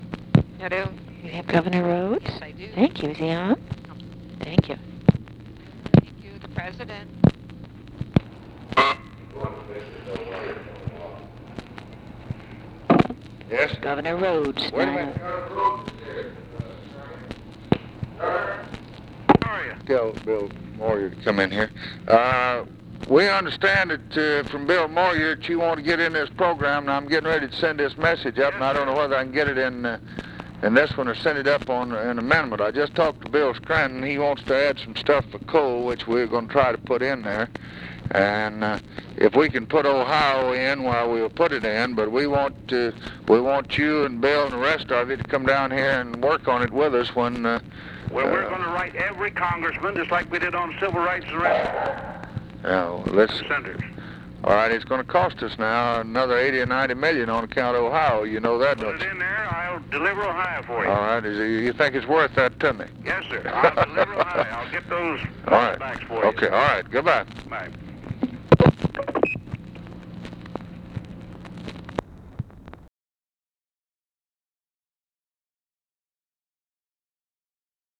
Conversation with JAMES RHODES, April 28, 1964
Secret White House Tapes